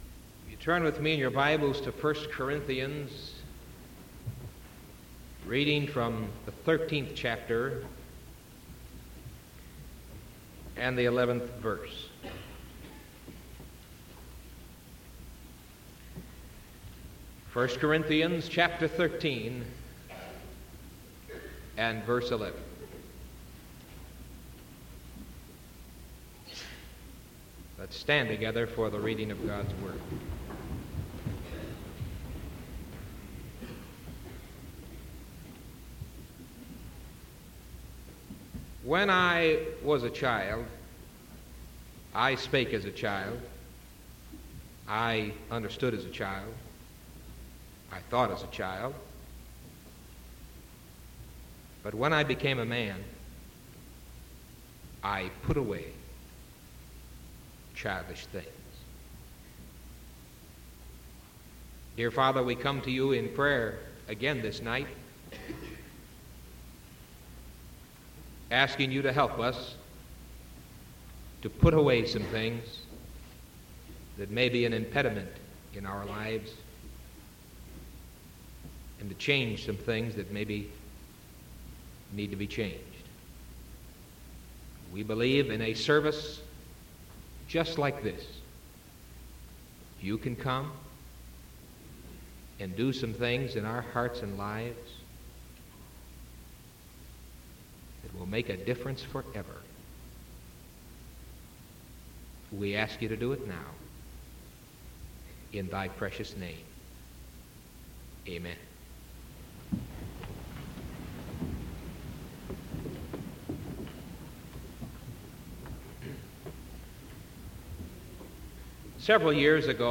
Sermon October 6th 1974 PM